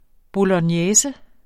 bolognese substantiv, fælleskøn Bøjning -n, -r, -rne Udtale [ bolʌnˈjεːsə ] Oprindelse fra italiensk bolognese 'fra Bologna' Betydninger 1.